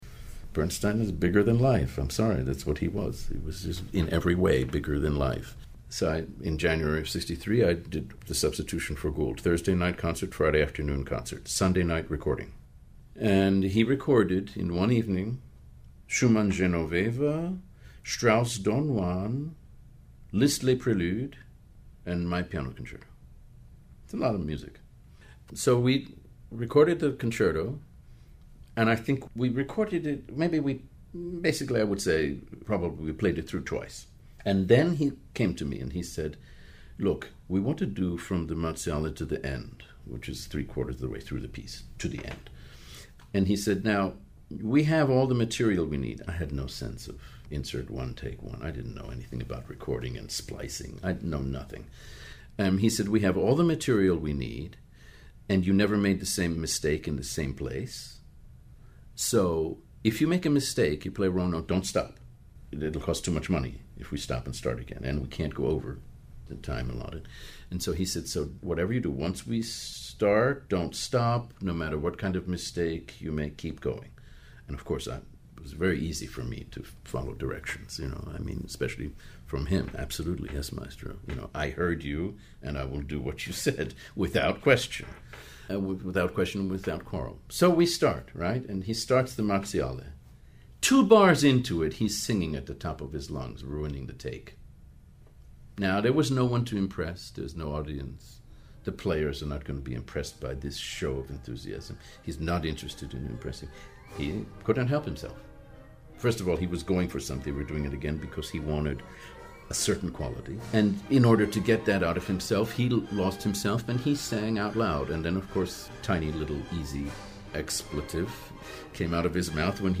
In this excerpt from the podcast, Watts recalls the experience of recording the concerto two days after the performance with the New York Philharmonic and the extraordinary Leonard Bernstein. [4:02]